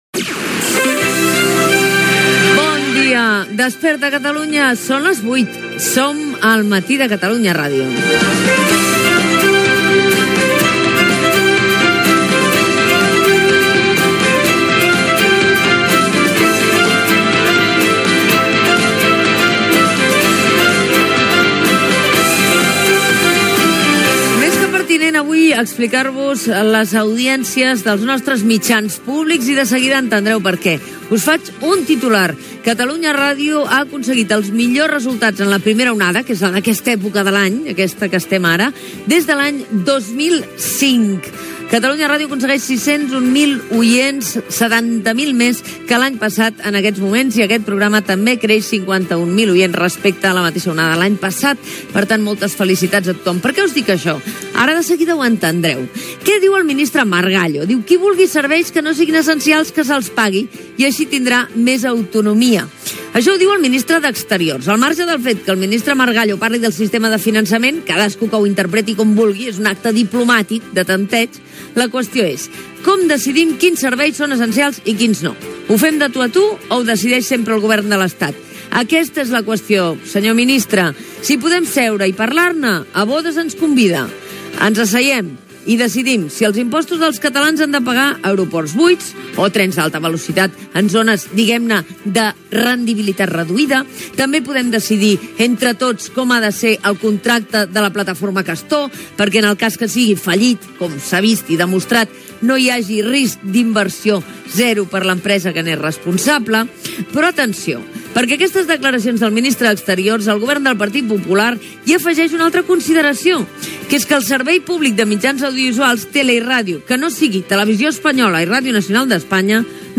Hora, salutació de l'hora, audiència de Catalunya Ràdio.
Info-entreteniment